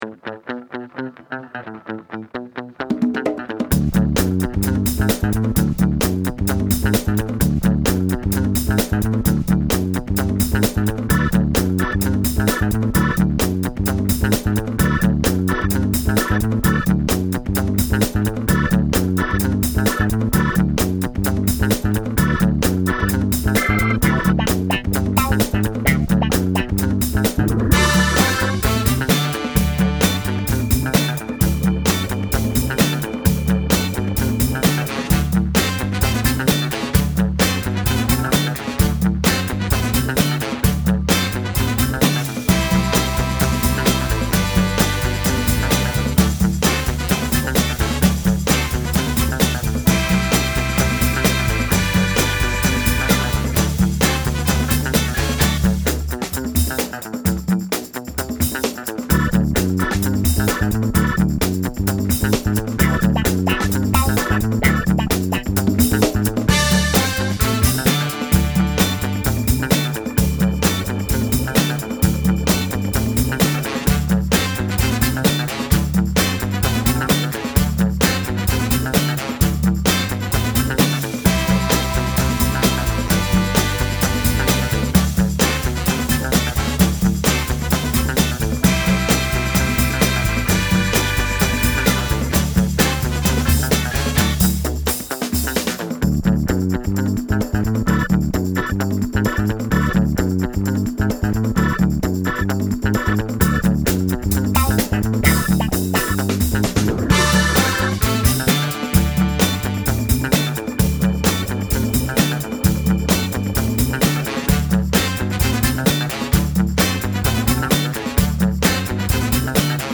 Sonidos: Música